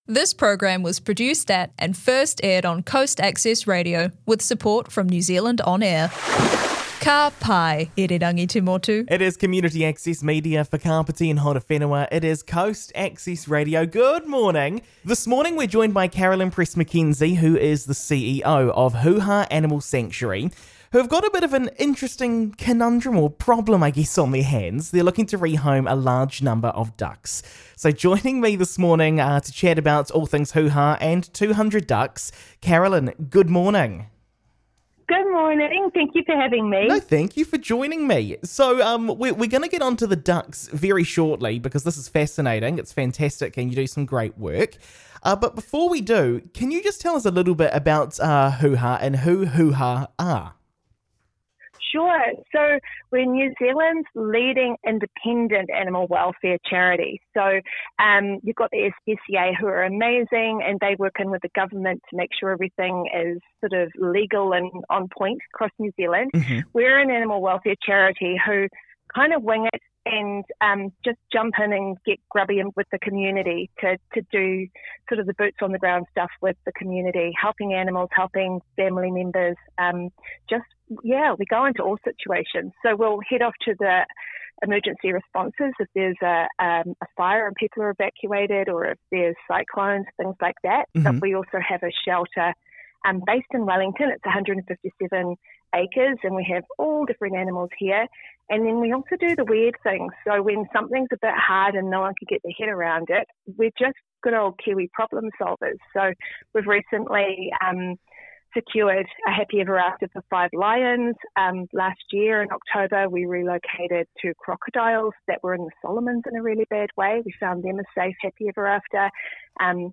Guests pop in and chat, news worthy events happen, timely interviews held on air - and they are captured here for you to enjoy if you missed them live.
This episode was first broadcast on Coast Access Radio, a Community Access Media Alliance station amplifying the voices of Kāpiti and Horowhenua, proudly supported by NZ On Air.